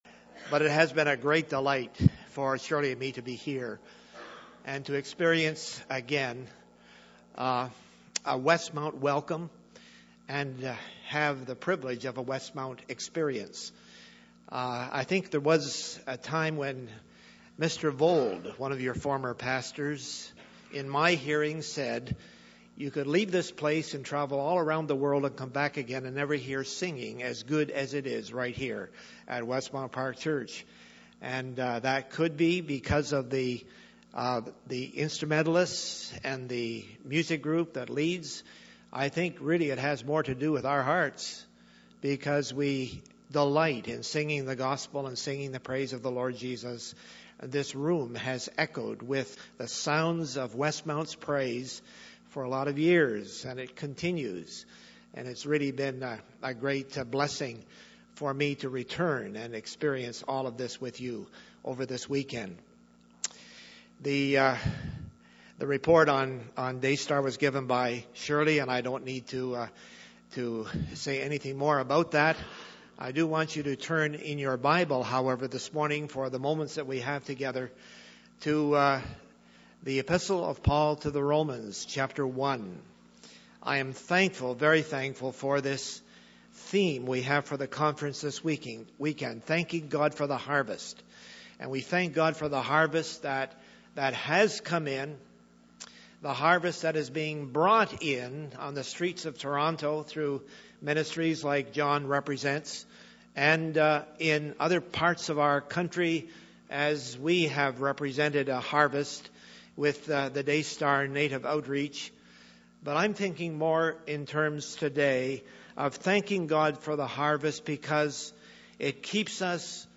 Podcast: Missions Conference Sunday Morning